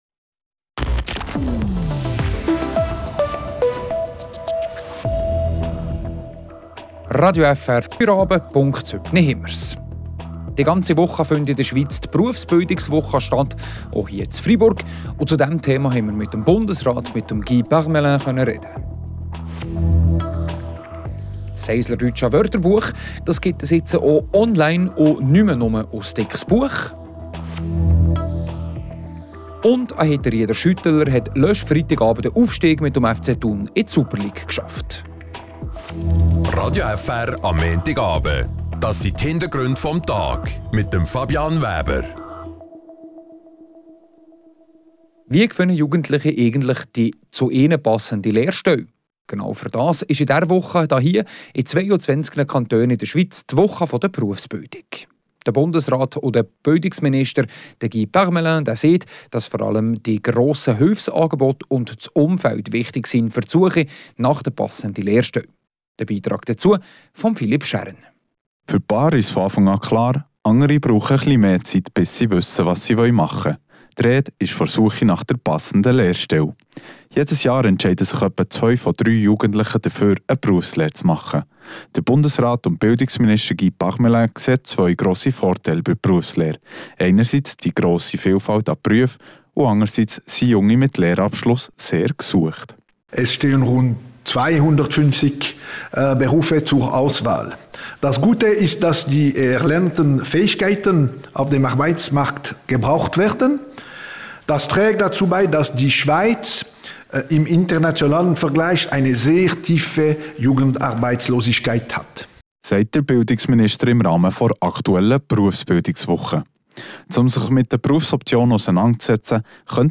In 22 Kantonen findet diese Woche die Berufsbildungswoche statt, auch in Freiburg. Darüber konnten wir mit Bundesrat und Bildungsminister Guy Parmelin sprechen.